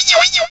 Cri de Blizzi dans Pokémon Diamant et Perle.